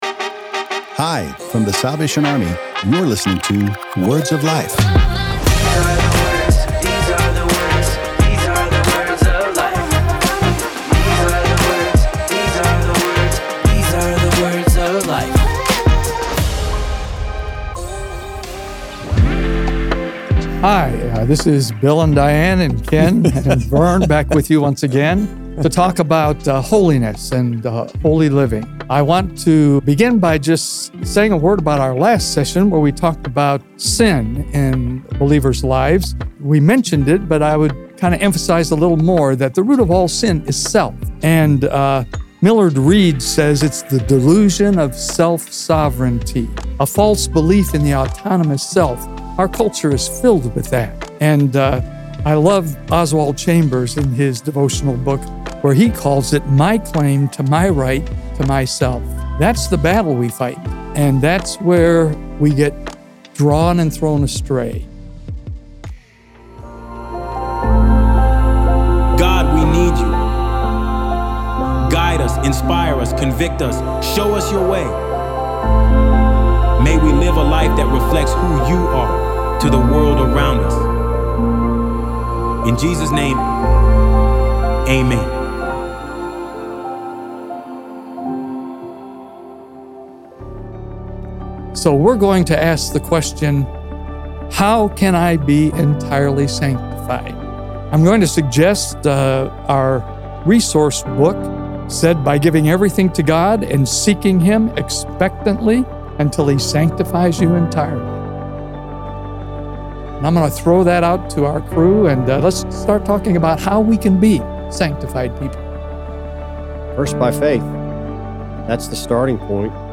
In this episode in our series about holiness the panel discusses the process in which a believer can become entirely sanctified.